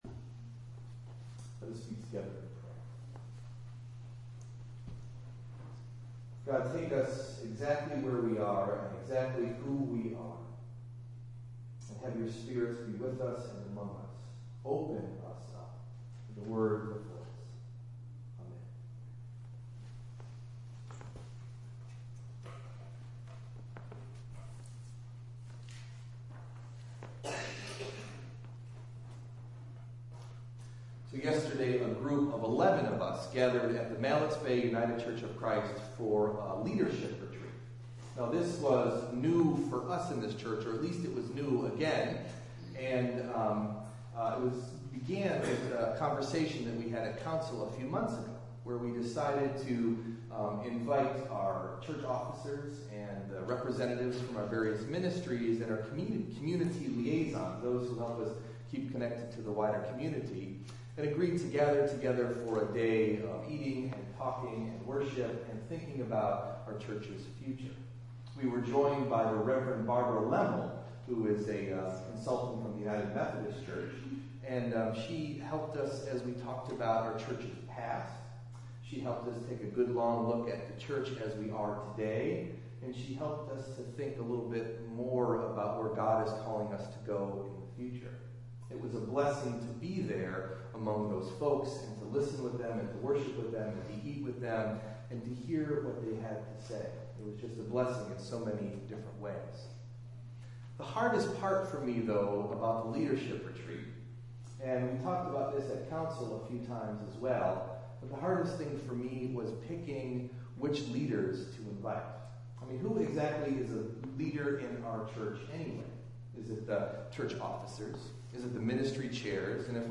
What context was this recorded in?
Delivered at: The United Church of Underhill (UCC and UMC)